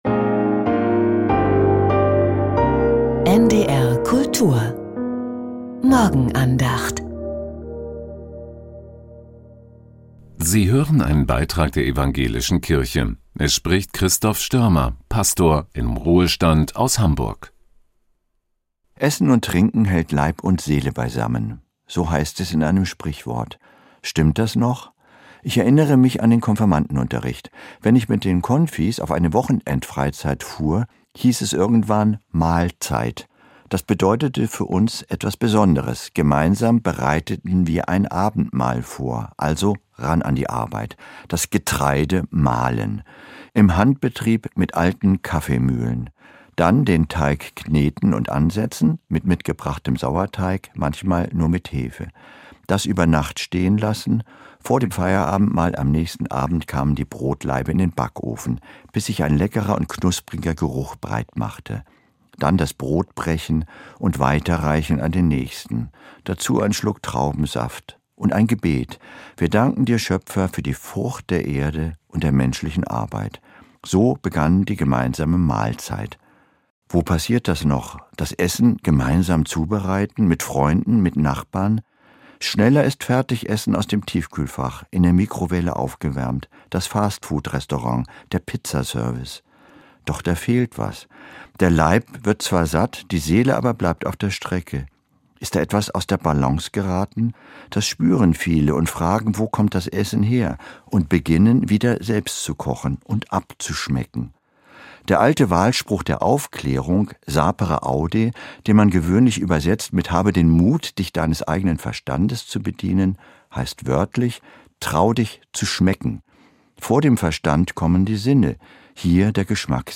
Leib und Seele ~ Die Morgenandacht bei NDR Kultur Podcast